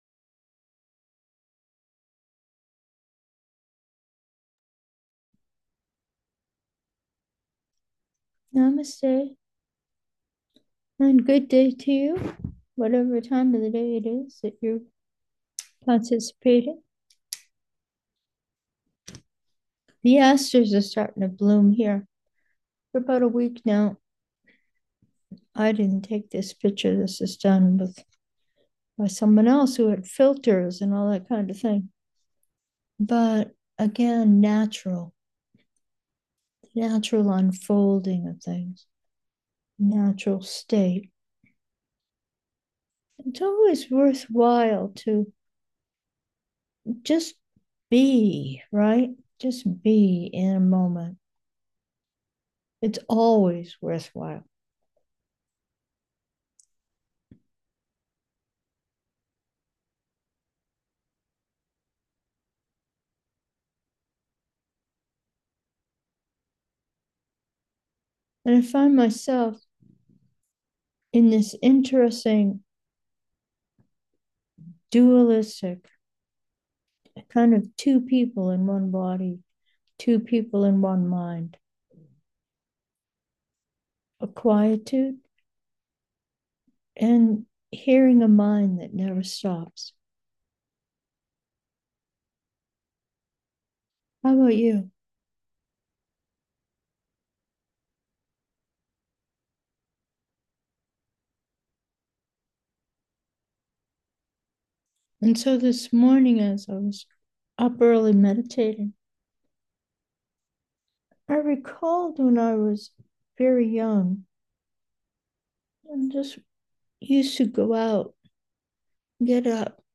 Meditation: two: embrace Now, aspiring to Be